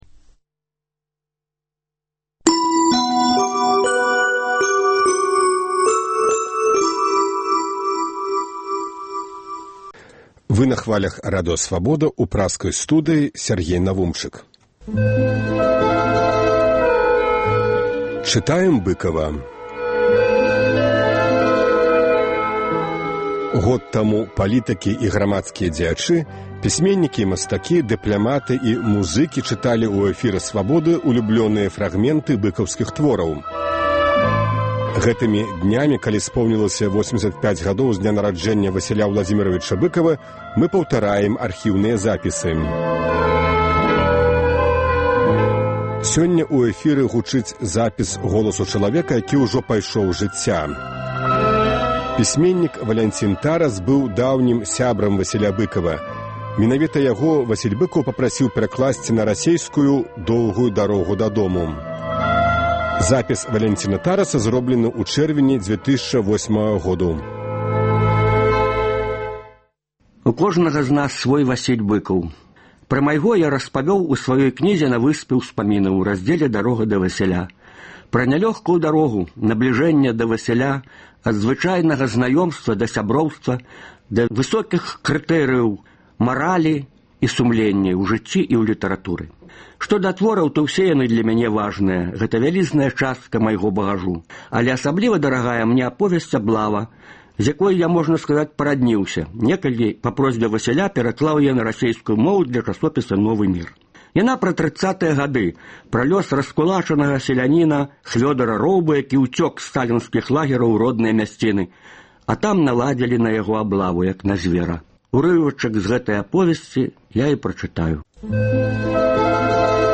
Вядомыя людзі Беларусі чытаюць свае ўлюбёныя творы Васіля Быкава. Сёньня гучыць запіс голасу чалавека, які ўжо пайшоў з жыцьця.
Сяргей Законьнікаў чытае ўрывак з апавядаеньня Васіля Быкава “На чорных лядах”.